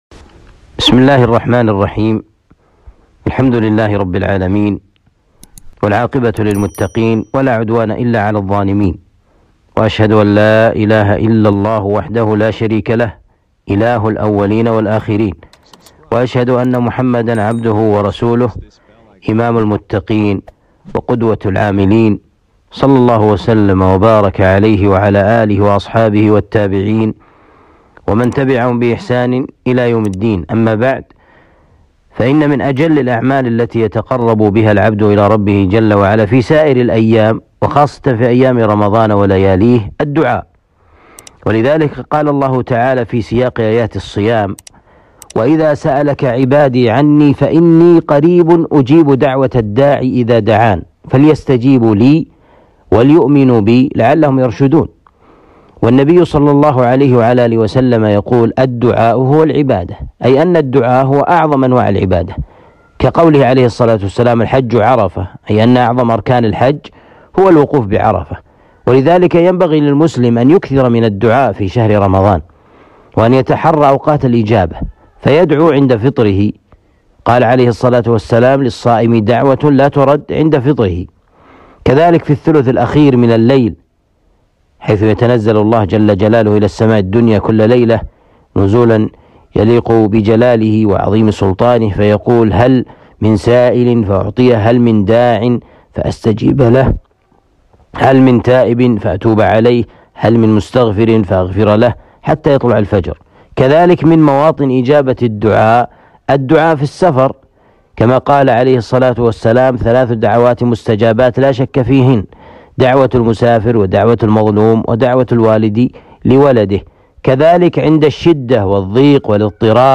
كلمة قصيرة بعنوان فضل الدعاء وتحري أوقات الإجابة